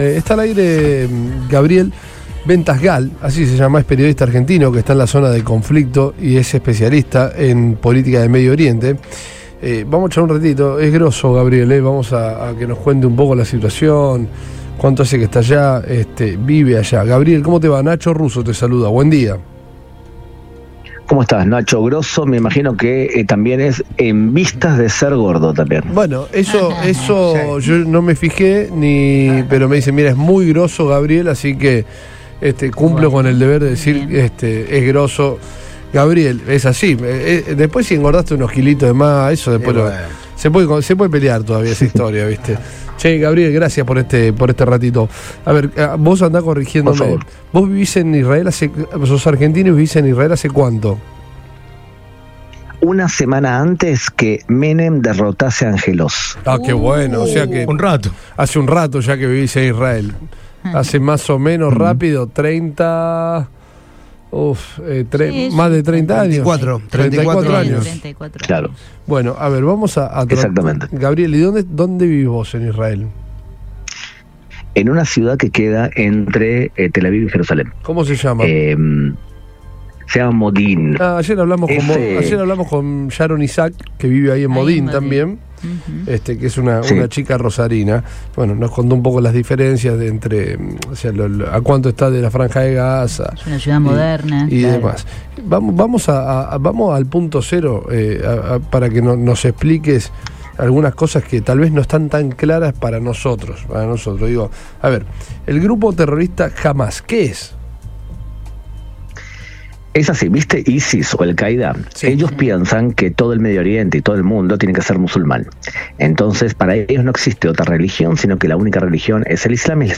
dialogó con el equipo de Todo Pasa por Radio Boing, donde explicó la situación con la claridad que lo caracteriza.